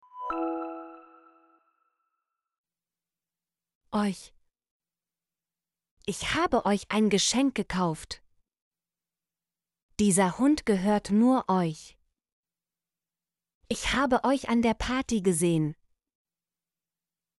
euch - Example Sentences & Pronunciation, German Frequency List